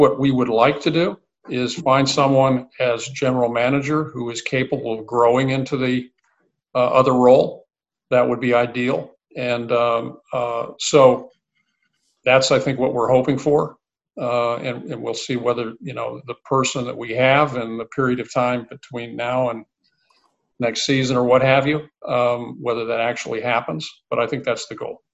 Sandy Alderson made 2 major announcements in his Zoom call with reporters yesterday–one was expected and the other was a bit surprising.